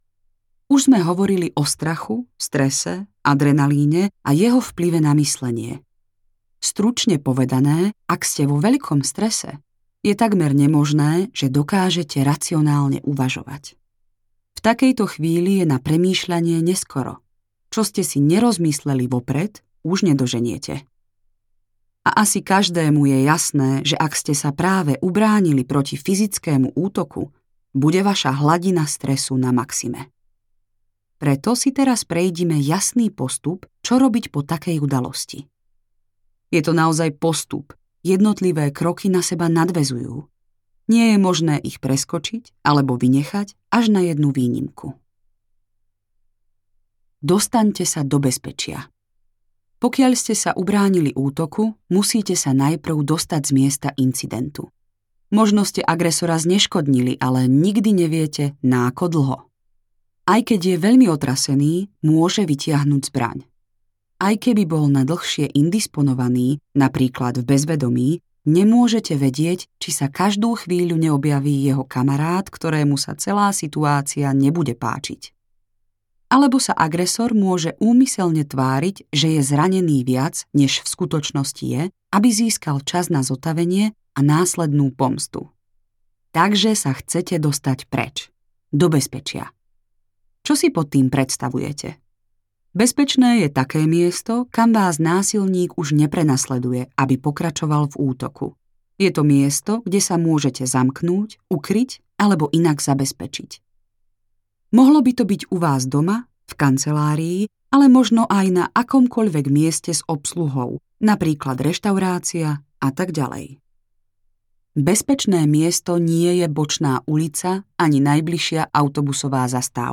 Audiokniha Moderná sebaobrana
audiokniha